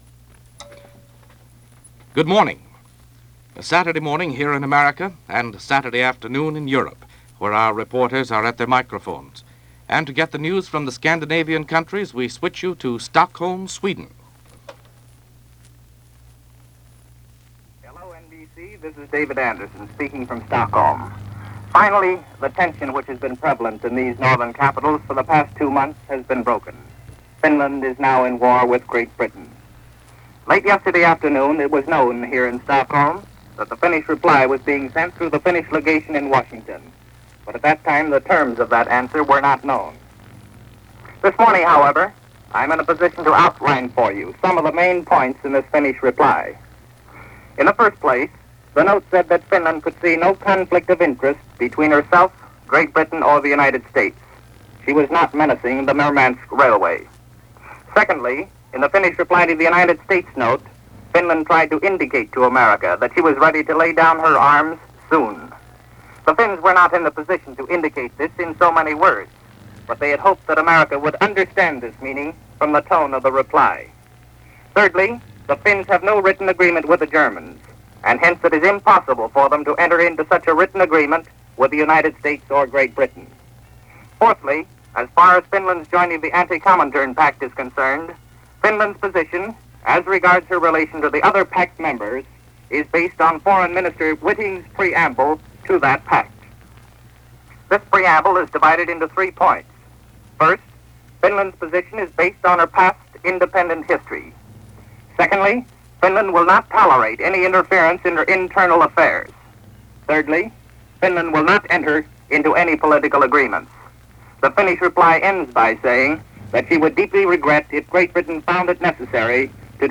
December 6, 1941 – News Of The World + Interview with Maxim Litvinov and his wife